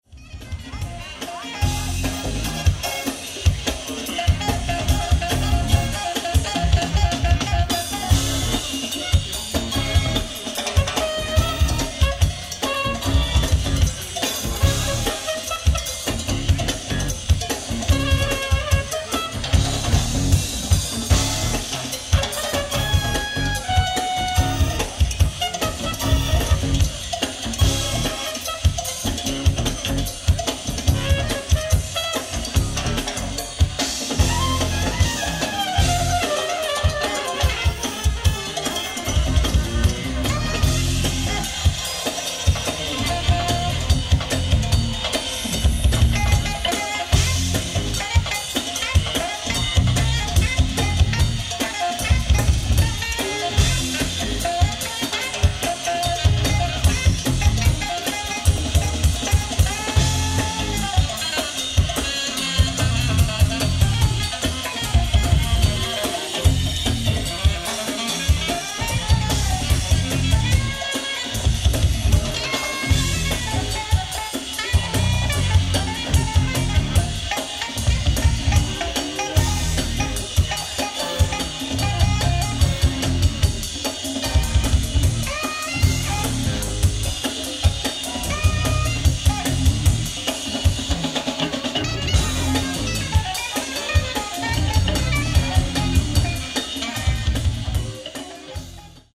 ライブ・アット・ポール・マッソン、ワイナリー、サラトガ、カリフォルニア 06/17/1989
海外マニアによる音抜け等の修正レストア音源を初収録！！
※試聴用に実際より音質を落としています。